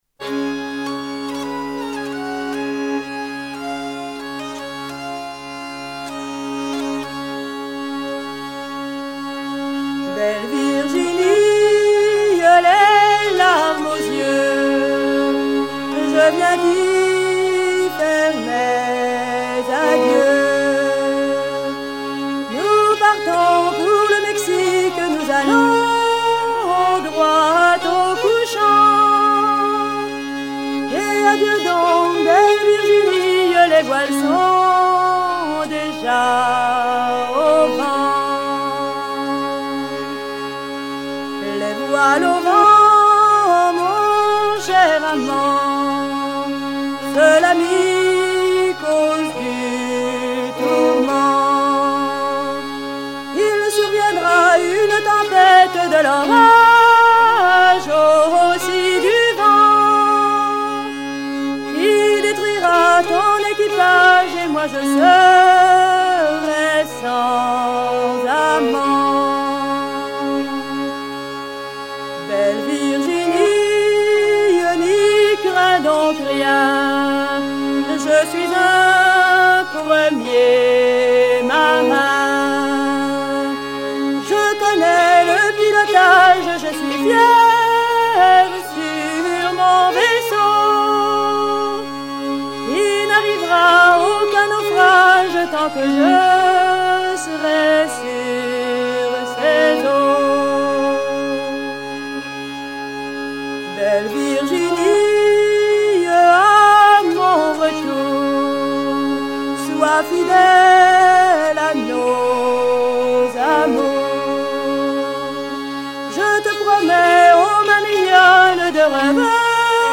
Version recueillie en 1970
Genre strophique